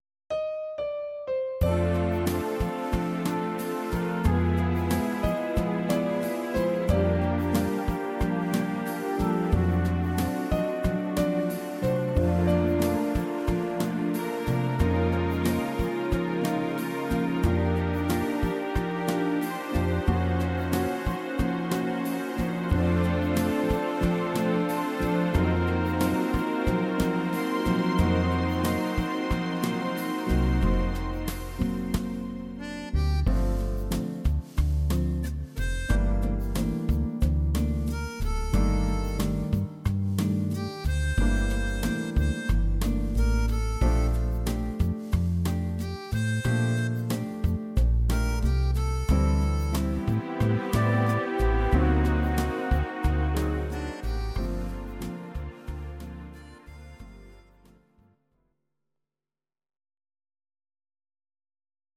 Audio Recordings based on Midi-files
Pop, Jazz/Big Band, 1990s